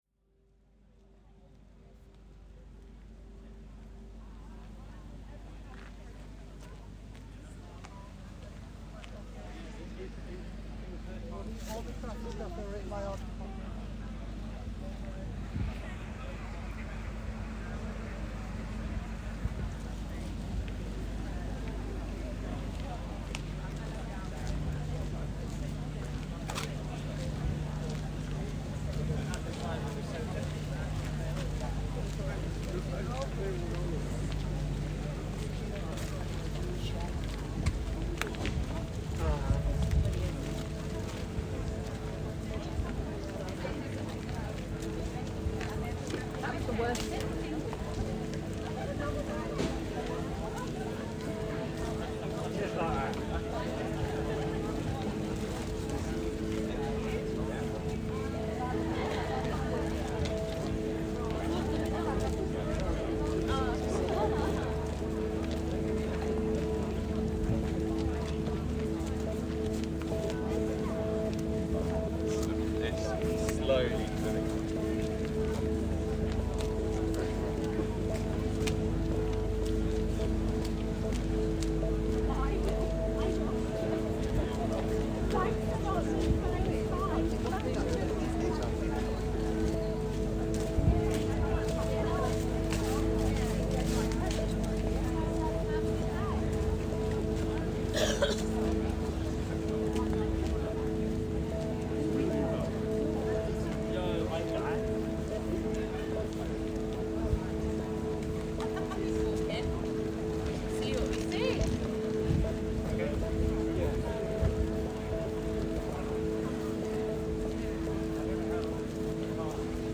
Procession of the Queen's coffin on The Mall, London